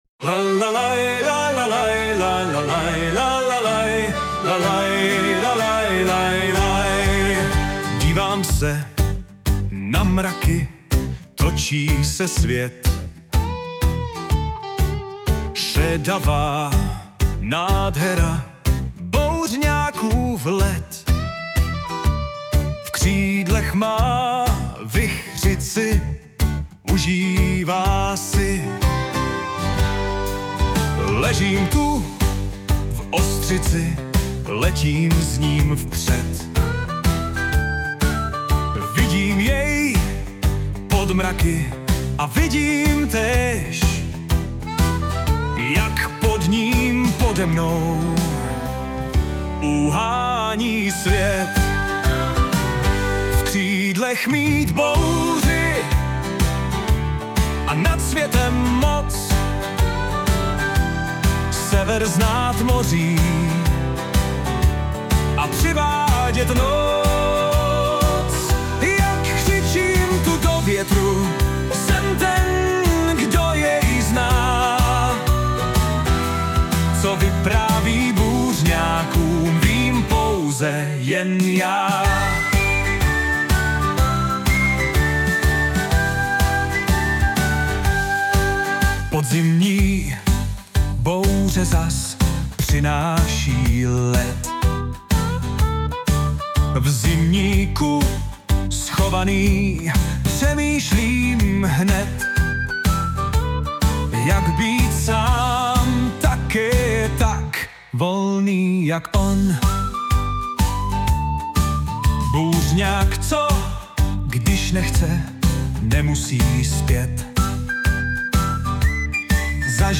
Anotace: Tahle tu ve starší podobě také už je - Taková lehce melancholická do hospody a podzimního počasí v irském přístavu.